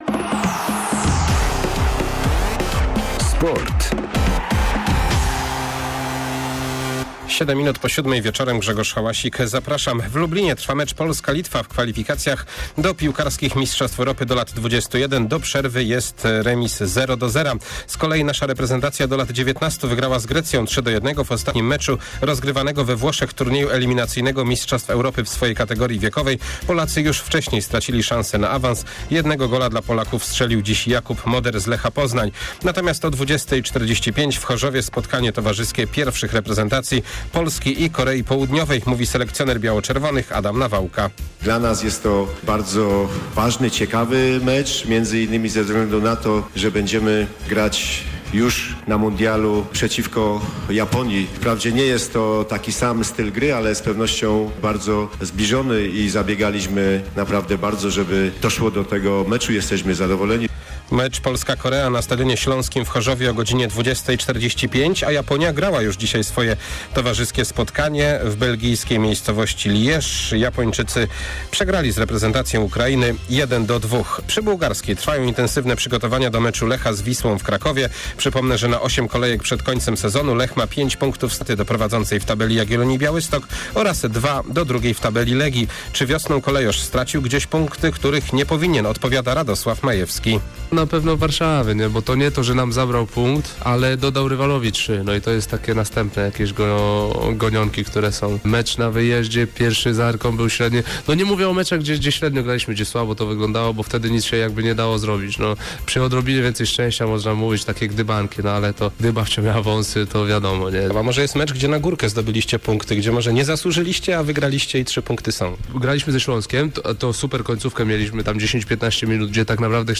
27.03 serwis sportowy godz. 19:05